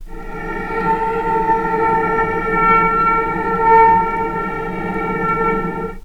vc_sp-A#4-pp.AIF